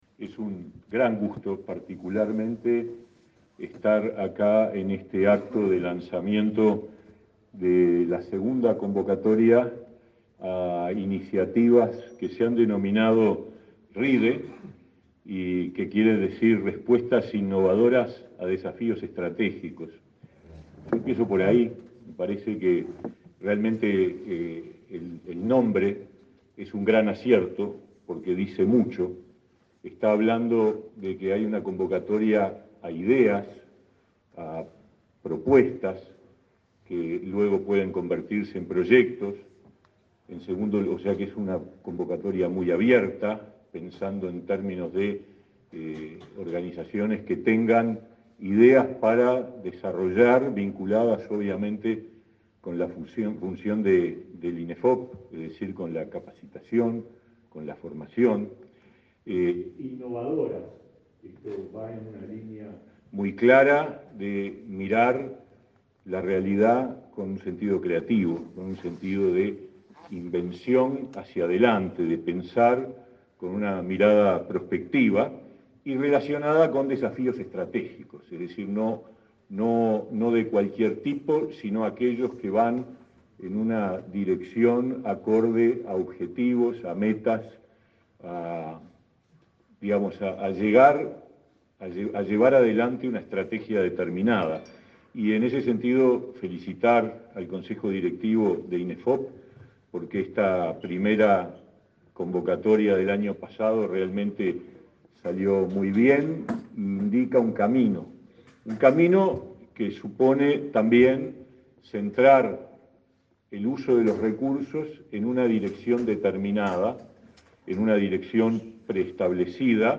Palabras del ministro de Trabajo, Pablo Mieres